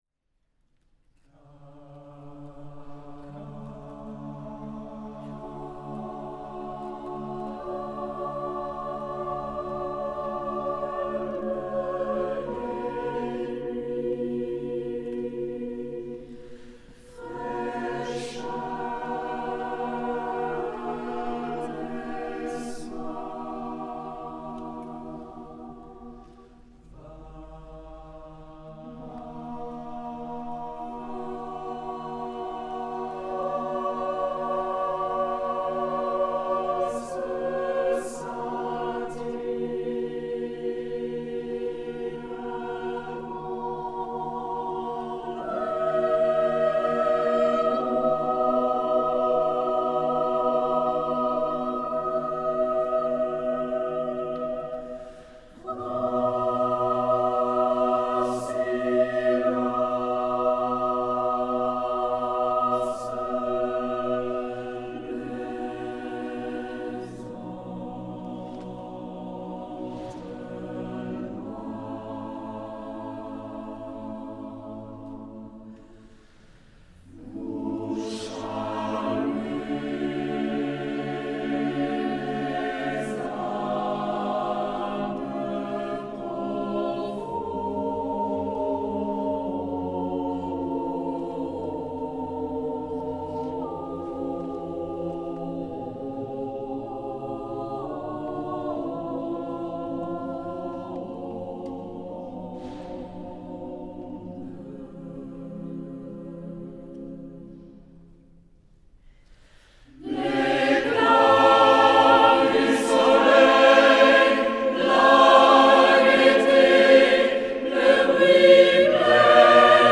Retrouvez ici des extraits « live » de nos concerts !
Camille Saint-Saëns – Calme des Nuits – Église Notre-Dame-du-Liban
Camille-Saint-SAENS-Calme-des-nuits-Calligrammes-2017-Concert-NDdu-Liban-mp3.mp3